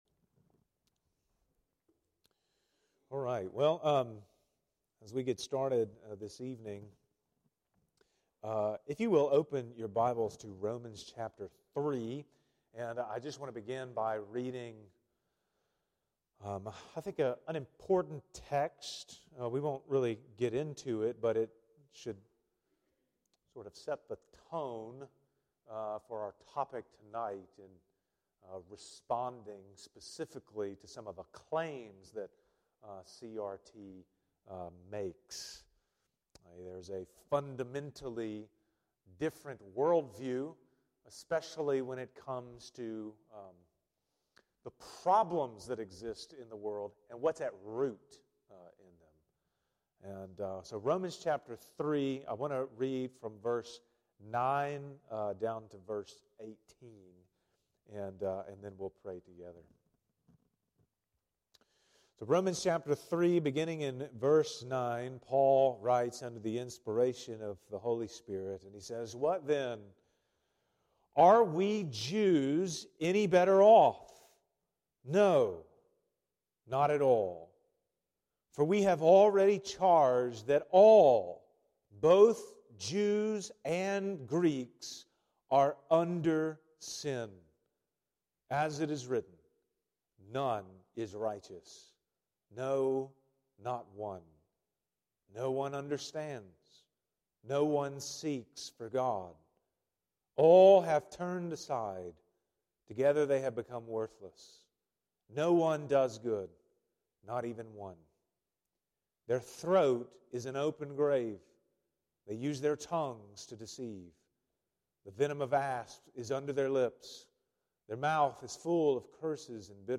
Sermons by Burton Memorial Baptist Church